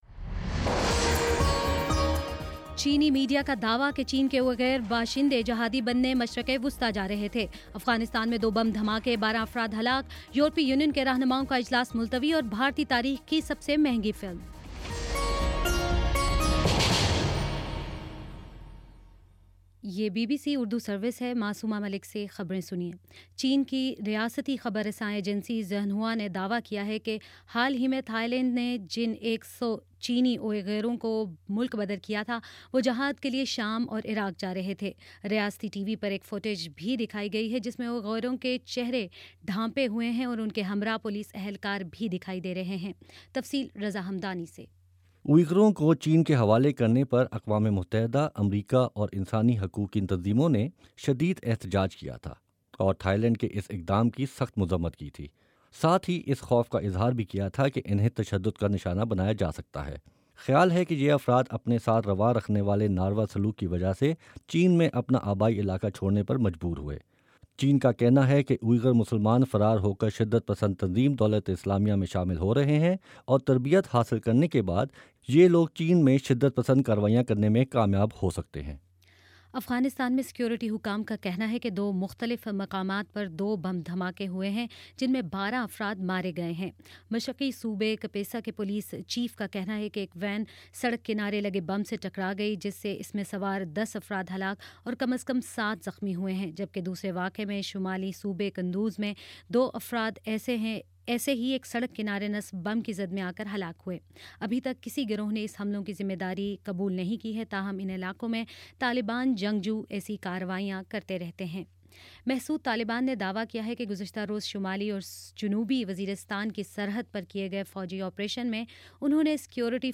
جولائی 12: شام چھ بجے کا نیوز بُلیٹن